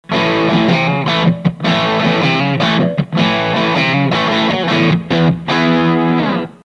Guitar Fender STRTOCASTER
Amplifier VOX AD30VT AC15
GAIN全開VOLUME全開
BOOSTER BYPASS(83kbMP3)